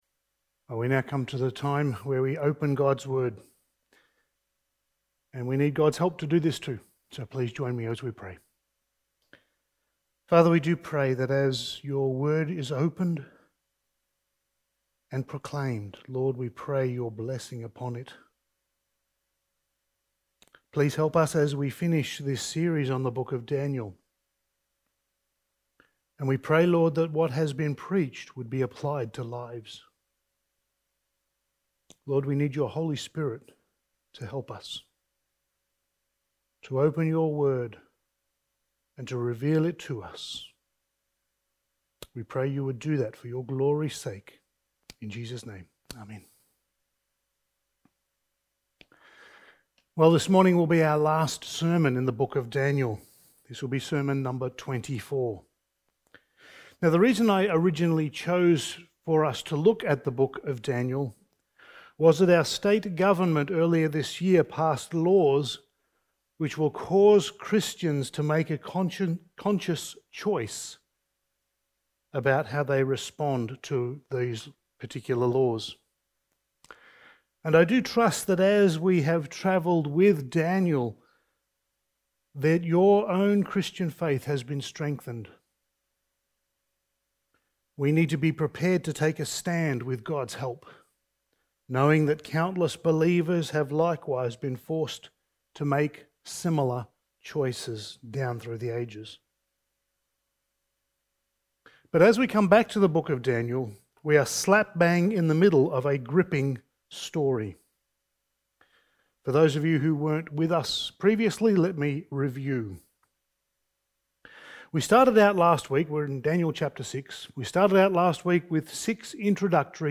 Passage: Daniel 6:16-28 Service Type: Sunday Morning